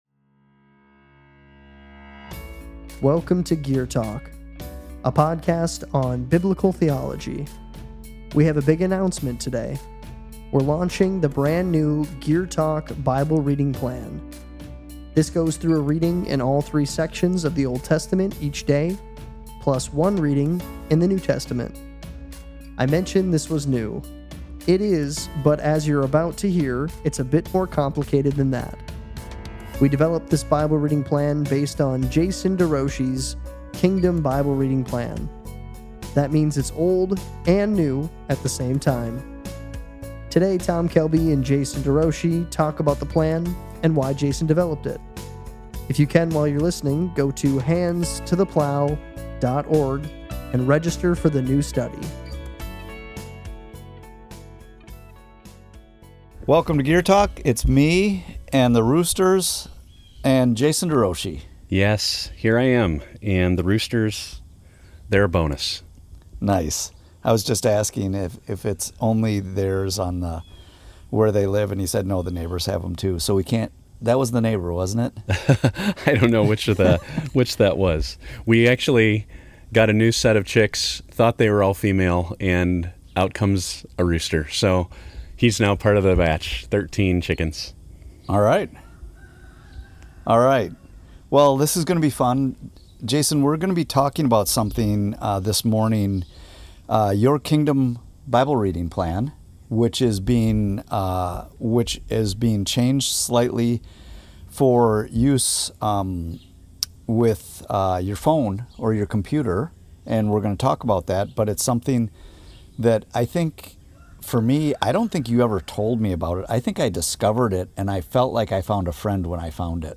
Rooster-GT-1.mp3